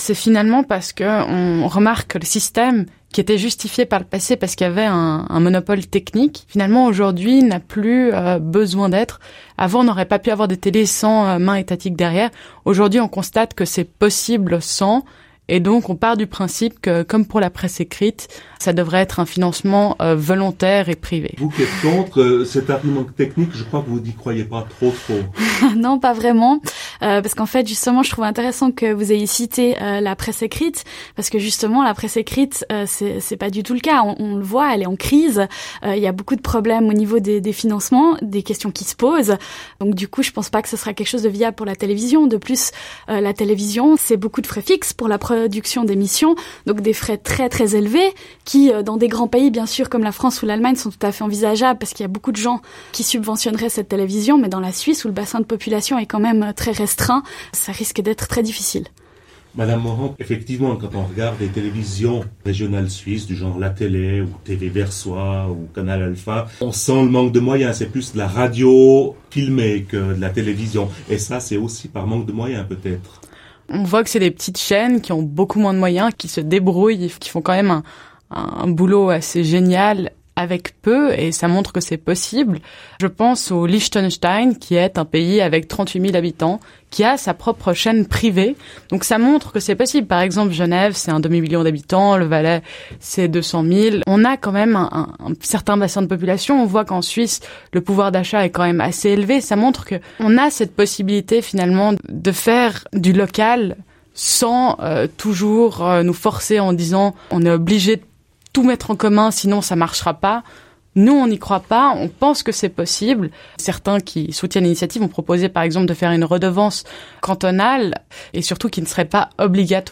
Débat contradictoire entre deux jeunes politiciennes sur No Billag